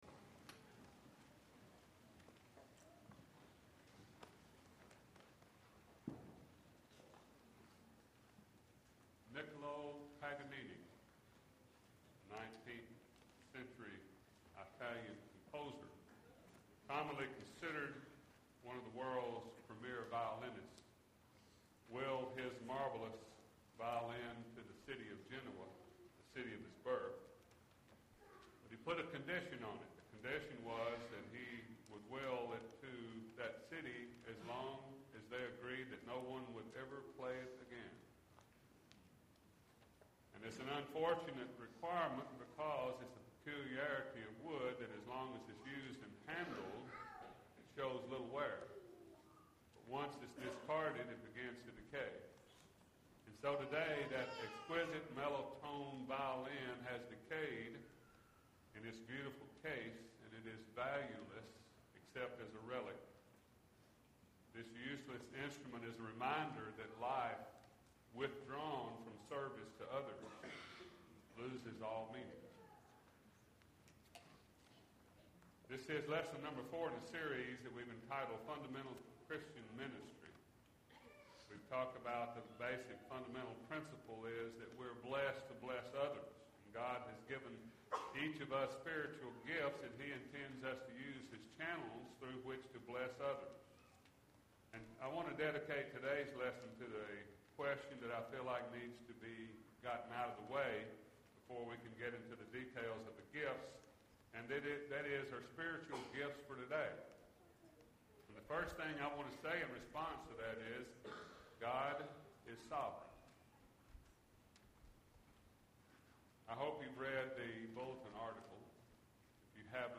Foundations of Christian Ministry (4 of 20) – Bible Lesson Recording